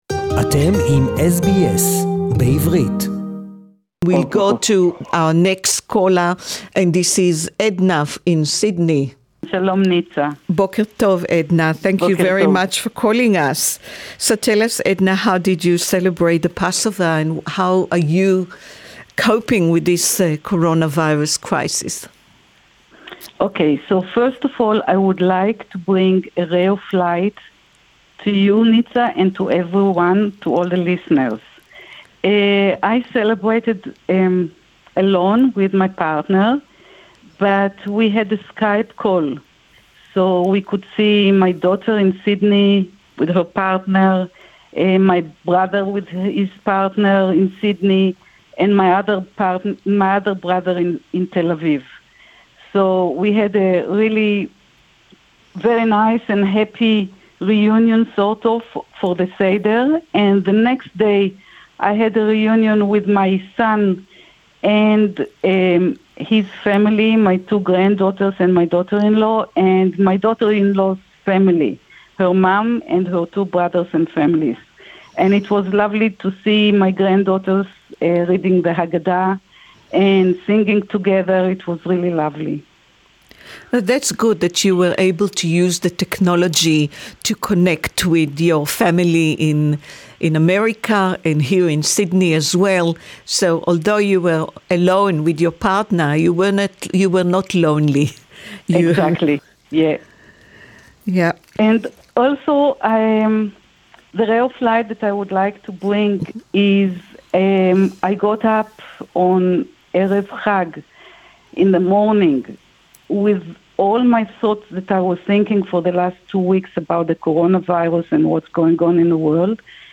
English Talk-back